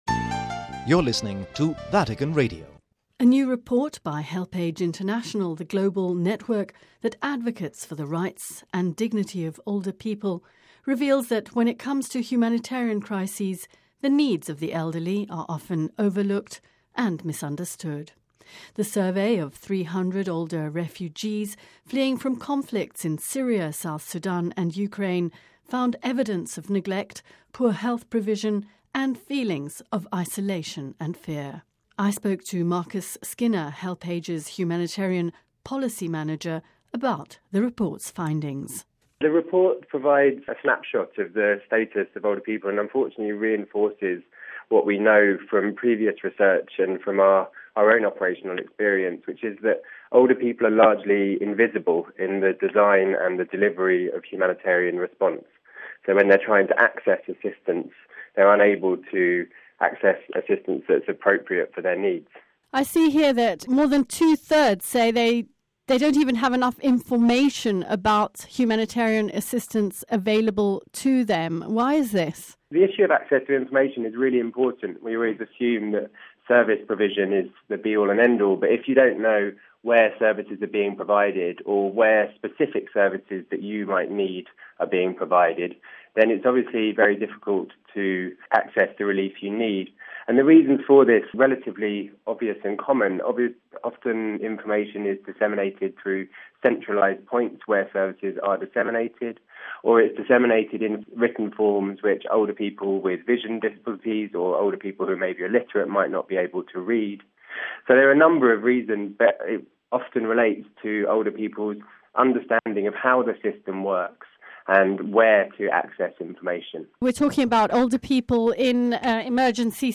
(Vatican Radio)  A new report by HelpAge International, the global network that advocates for the rights and dignity of older people, reveals that when it comes to humanitarian crises, the needs of the elderly are often overlooked and misunderstood. The survey of 300 older refugees fleeing from conflicts in Syria, South Sudan and Ukraine found evidence of neglect, poor health provision and feelings of isolation and fear.